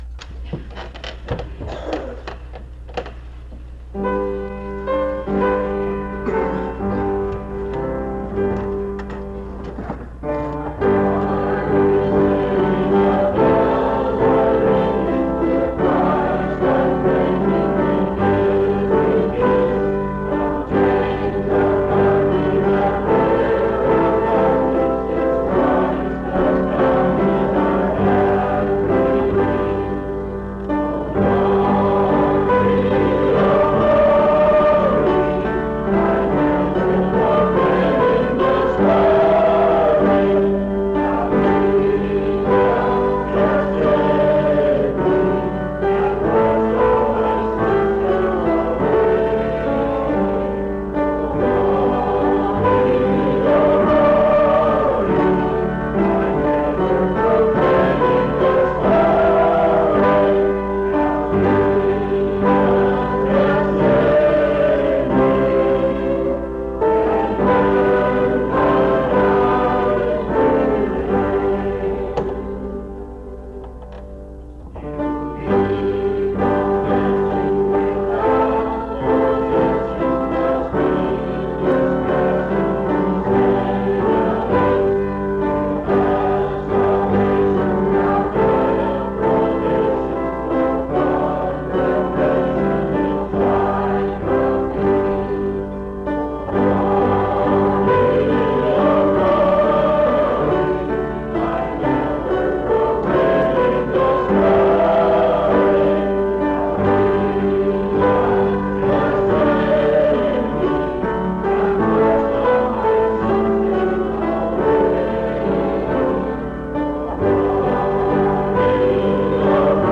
My Heart is Singing Item cffbf30da700ba698097dc0ca8dcec38adc0188e.mp3 Title My Heart is Singing Creator Spiritual Life Tabernacle Choir Description This recording is from the Miscellaneous Collection, track 159N.